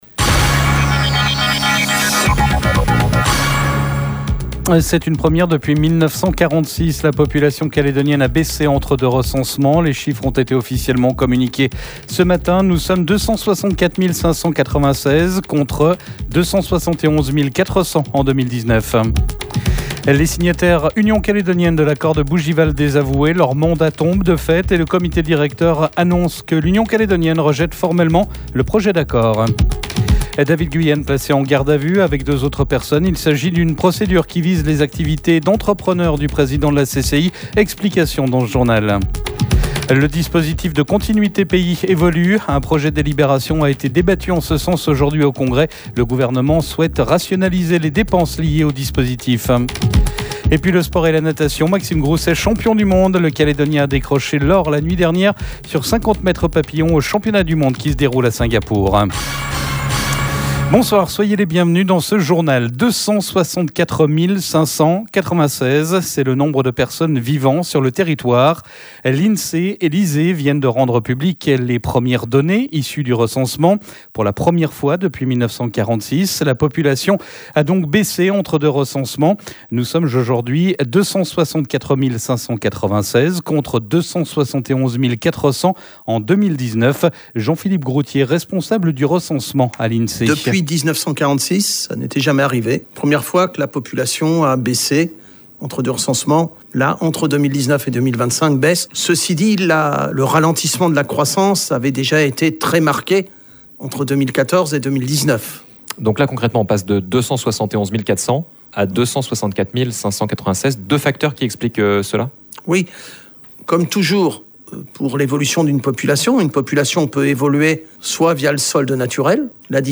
Le journal de la rédaction.